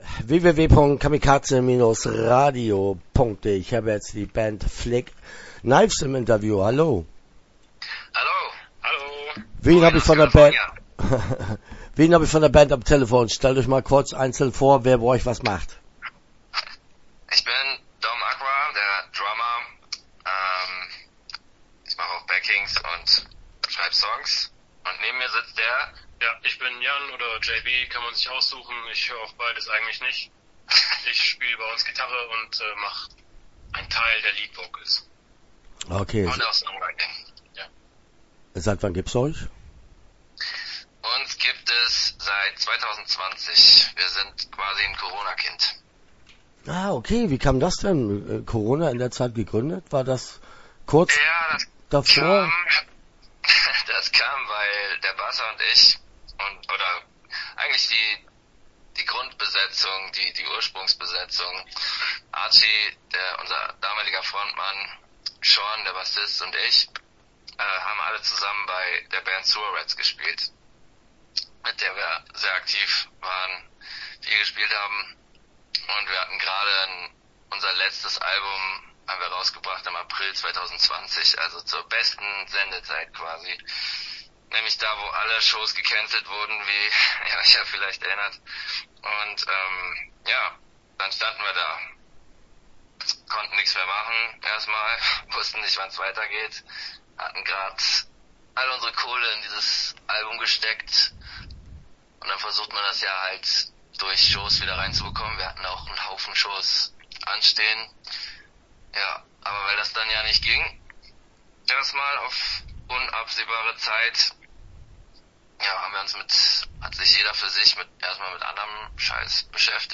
Flick Knives - Interview Teil 2 (20:31)